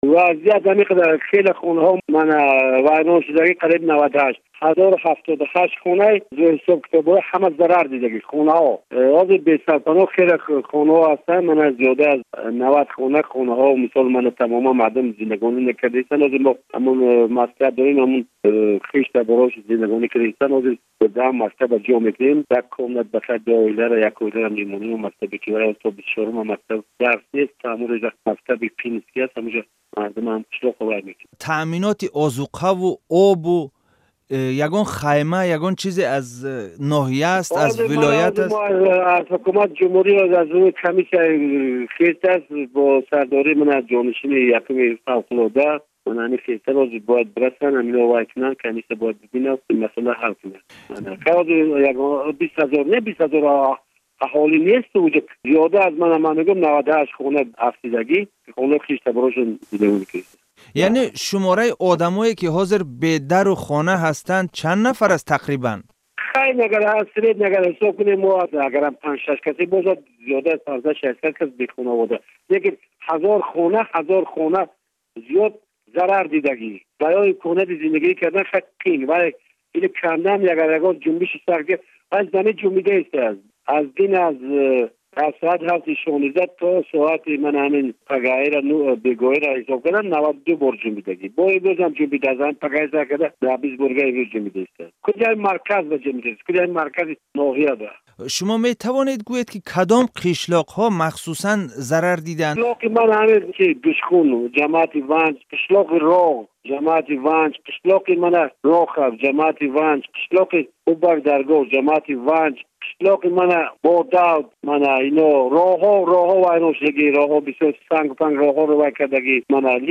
Иттилои телефонии раиси дастгоҳи ҳукумати ноҳияи Ванҷ, Атобек Субадоров